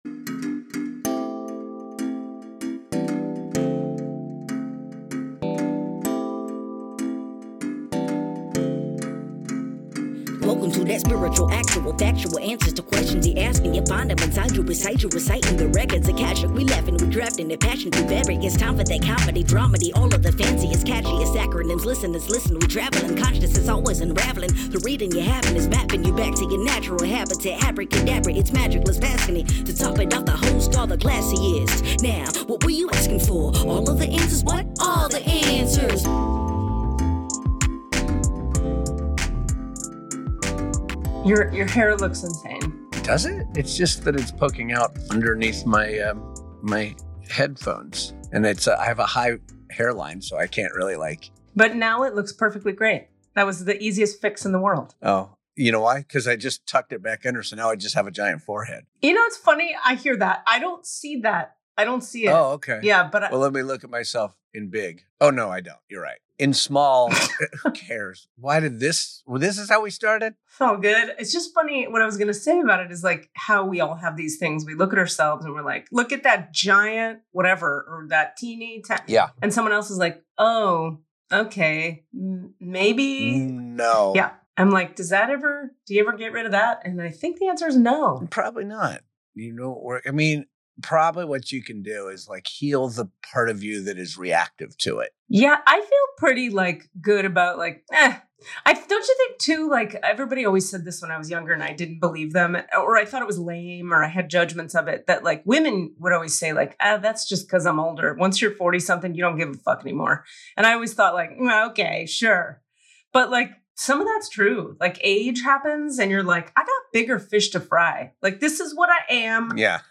Tune in for a thought-provoking discussion that invites you to reflect on your own journey of self-awareness and growth.